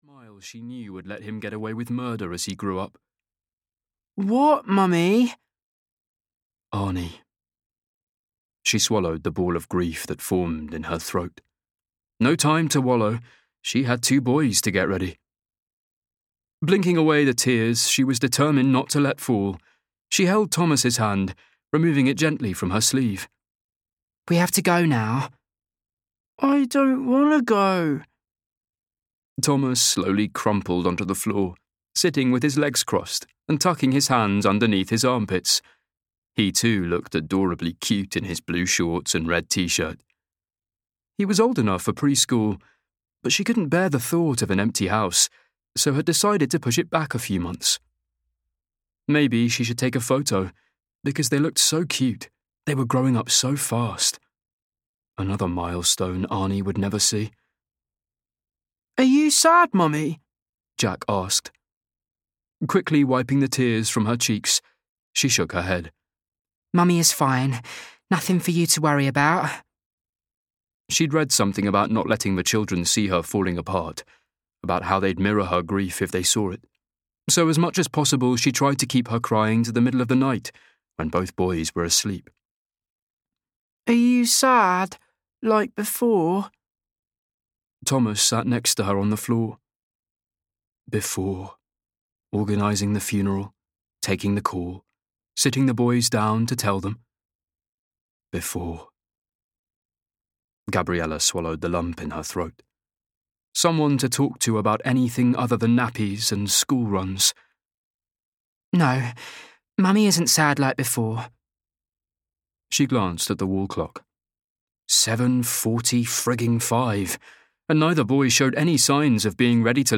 The Lonely Hearts Lido Club (EN) audiokniha
Ukázka z knihy